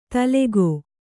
♪ talegō